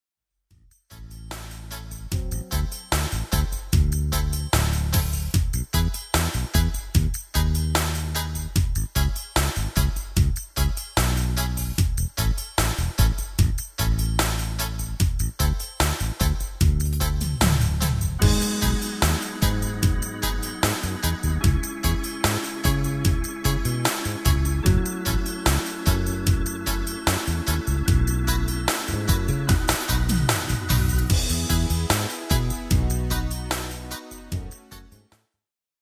Demo/Koop midifile
Genre: Reggae / Latin / Salsa
- Géén vocal harmony tracks